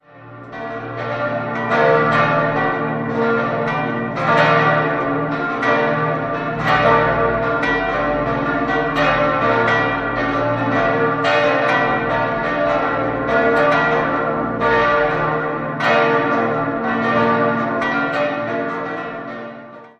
Die Neuweihe erfolgte auf den Namen des heiligen Josef. 5-stimmiges ausgefülltes Salve-Regina-Geläute: b°-c'-d'-f'-g' Alle Glocken wurden im Jahr 1947 von der Firma Oberascher in München gegossen.